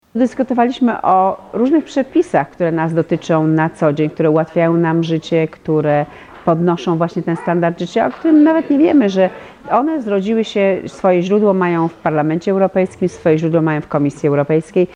– mówi eurodeputowana Danuta Jazłowiecka.